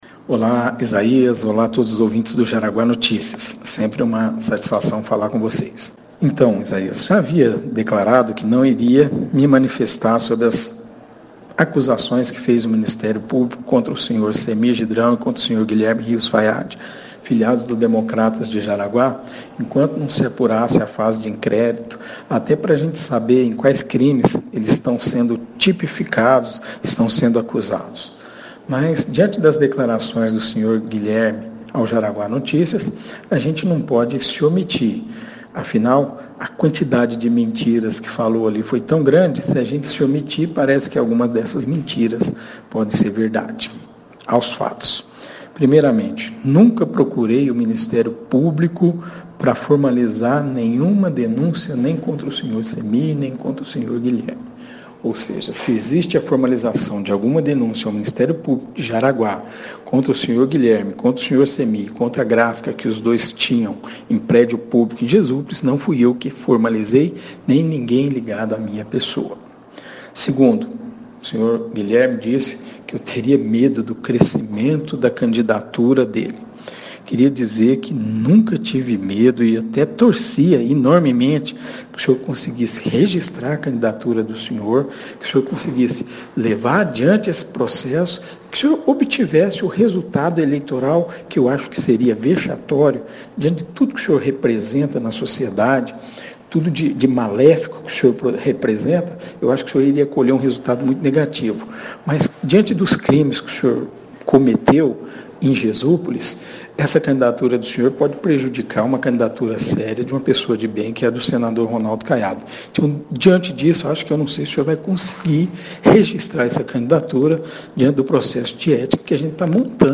Por se tratar de direito de resposta, a produção do Jaraguá Notícia não produziu o conteúdo escrito no teor do áudio enviado pelo próprio vereador Breno Leite, o que abrimos espaço apenas para inserção da resposta gravada neste blog de notícia, como segue abaixo: